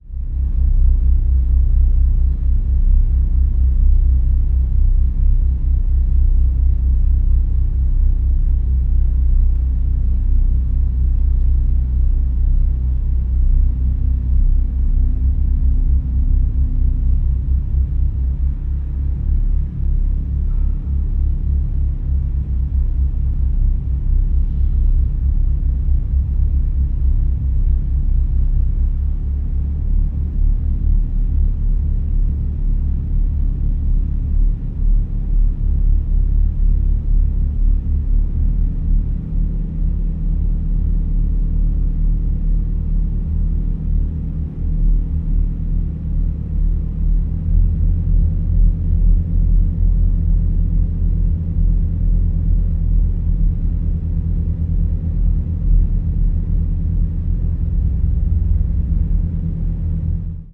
Rich and meditative, arcing past, present and future.
organ and quarter-tone accordion
cello
contrabass